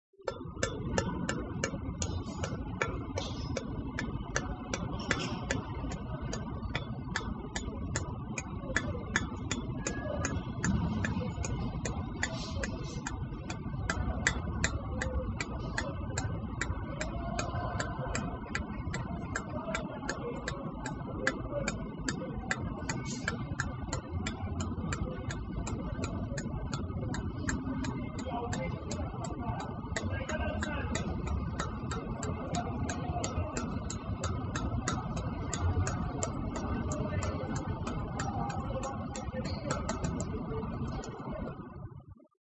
描述：este audio hace parte del foley de“the Elephant's dream”
Tag: 步行 金属 步骤